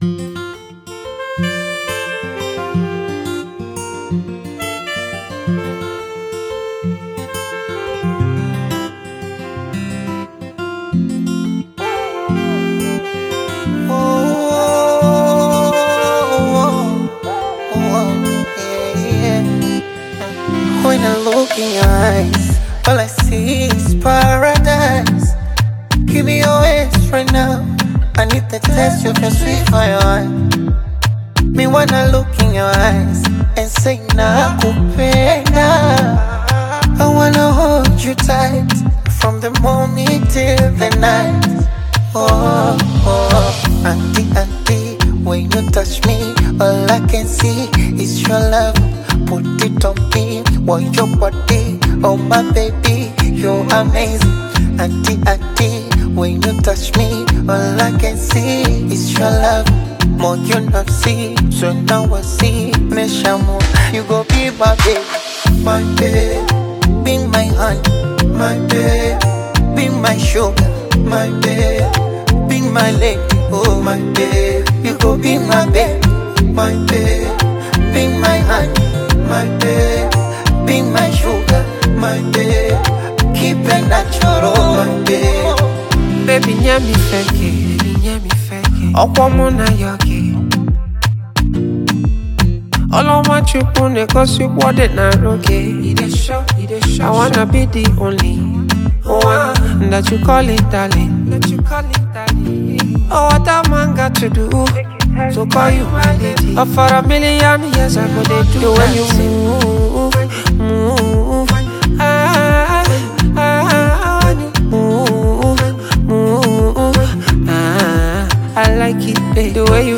Afro Highlife